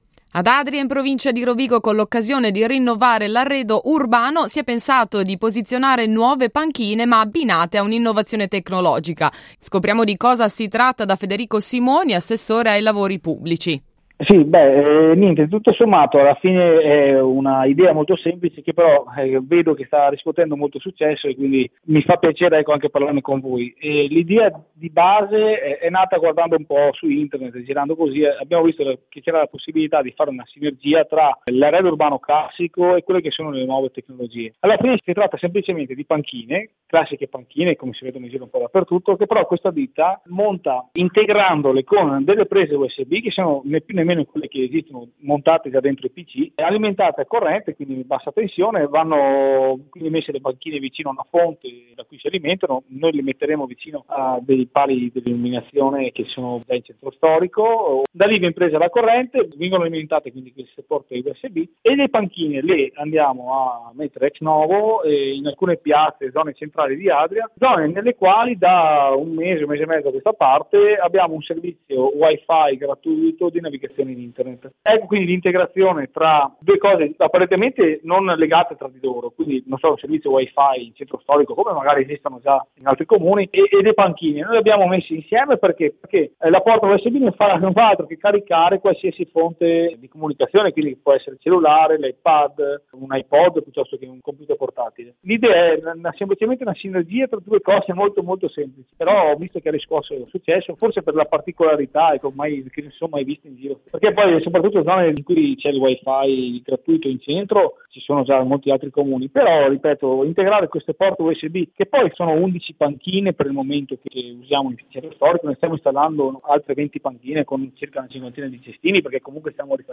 Ce ne ha parlato l’assessore ai lavori pubblici Federico Simoni.